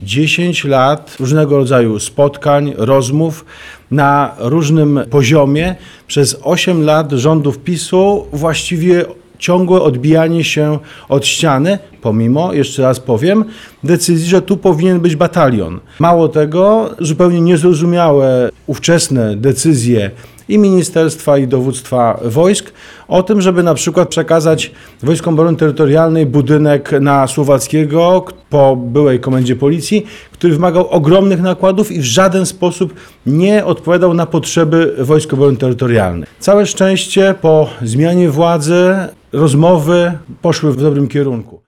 W poniedziałek, 15 grudnia, w Starostwie Powiatowym w Płocku odbyła się konferencja prasowa poświęcona podpisaniu aktu notarialnego dotyczącego obecności jednostki Wojsk Obrony Terytorialnej na terenie powiatu płockiego.
– podkreślał Prezydent Miasta Płocka Andrzej Nowakowski.